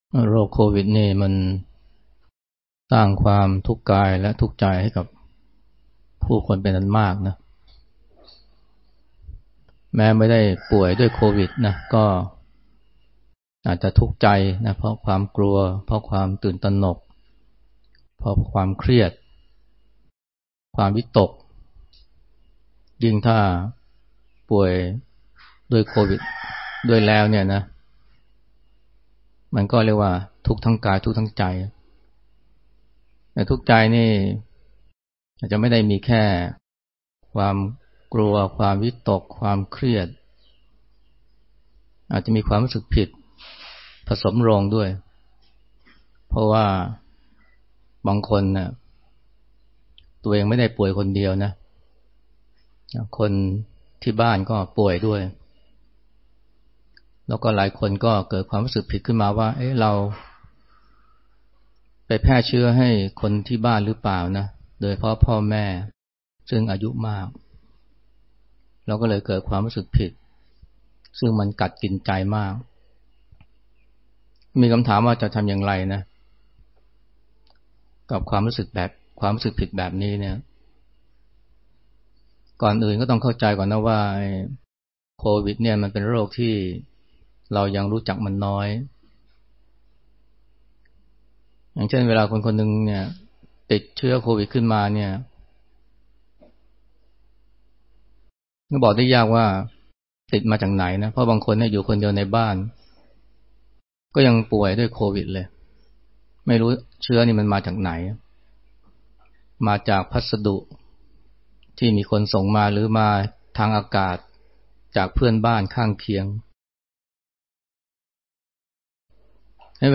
ฟังธรรมะ Podcasts กับ พระอาจารย์ไพศาล วิสาโล